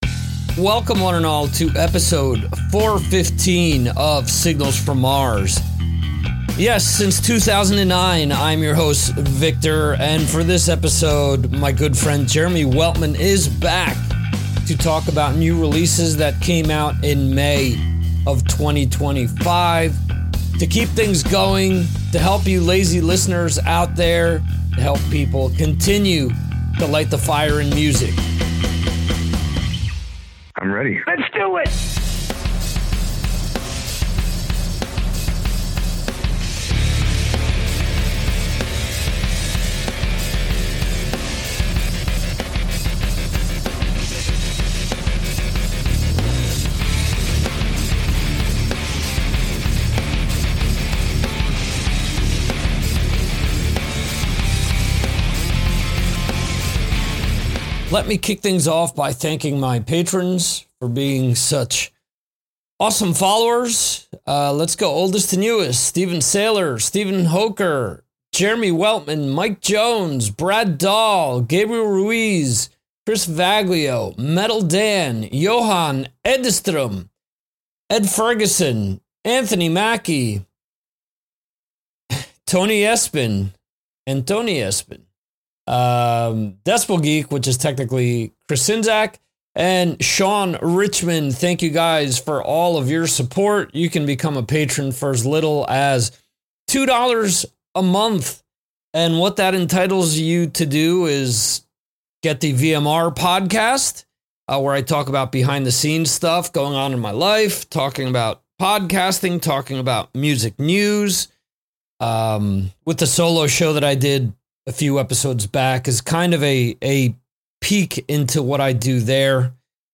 All types of hard rock and metal interviews and music discussions since 2009.